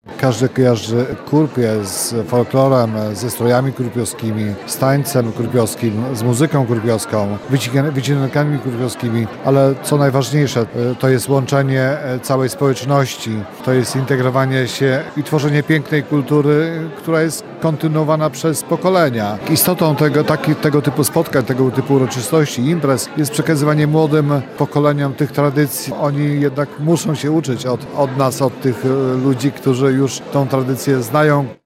Istotą tego typu spotkań jest przekazywanie młodemu pokoleniu wartości i tradycji – mówił podczas gali, starosta ostrołęcki,  Piotr Liżewski: